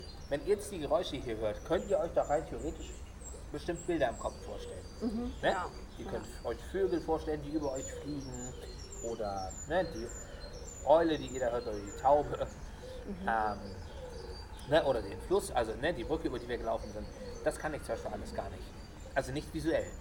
Blinde und sehbehinderte Guides begleiten Besuchende bei Dialog im Dunkeln durch lichtlose Räume.
Wir laufen durch die erste Tür und hören Vogelgezwitscher, Wind und das Geräusch eines Flusses.